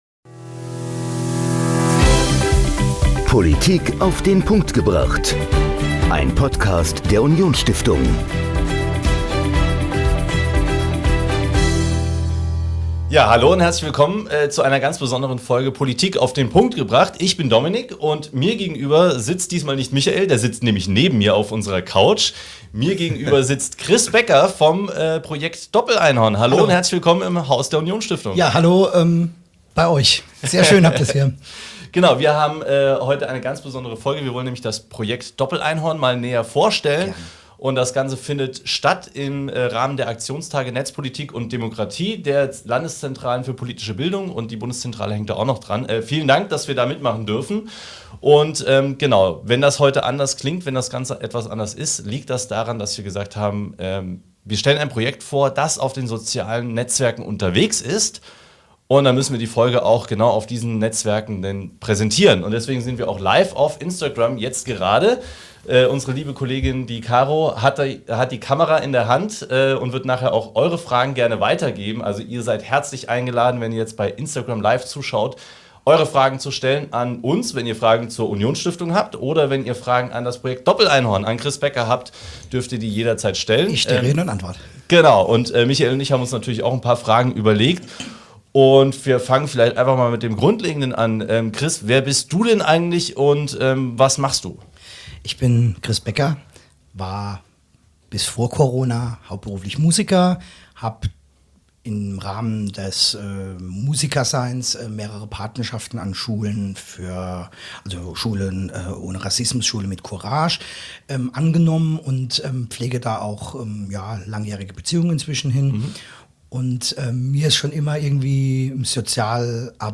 Die Folge wurde live auf Instagram ausgestrahlt.